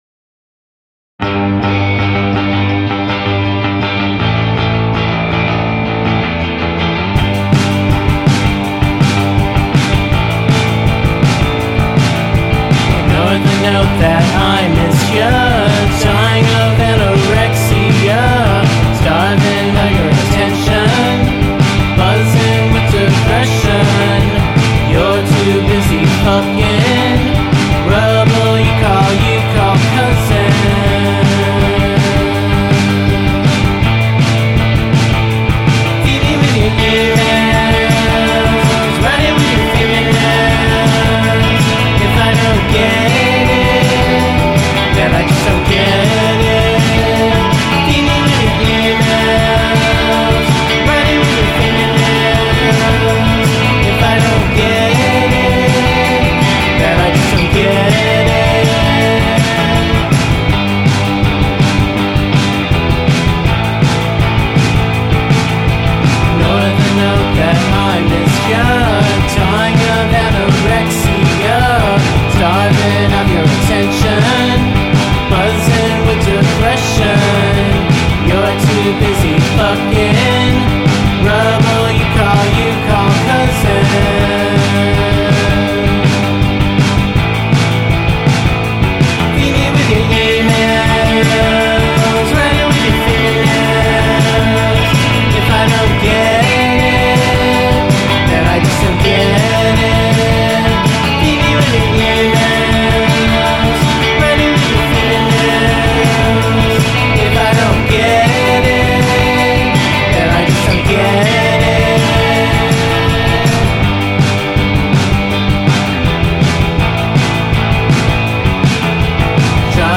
tre canzoni di jangle-pop perdente e vorticoso